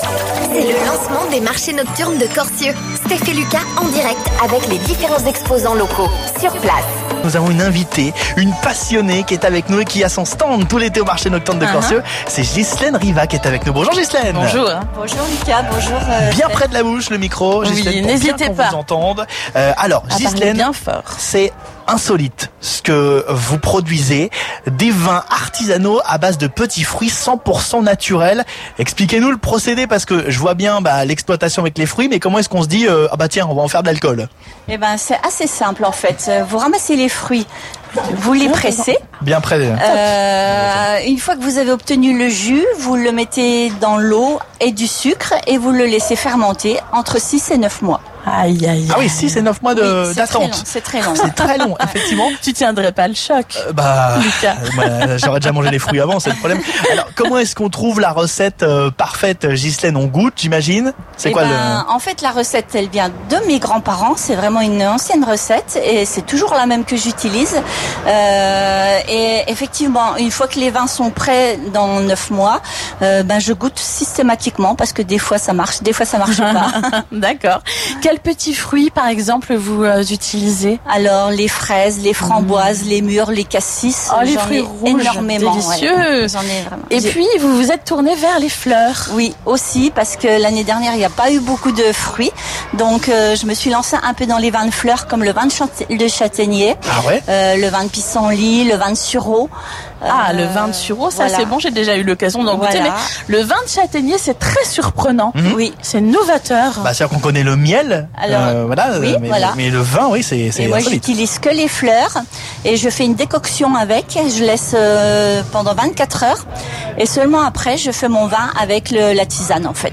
Emission spéciale
pour le début des marchés nocturnes de Corcieux !Des exposants locaux et énormément d'animations comme de la musique, de la danse ou des baptêmes en poney par exemple !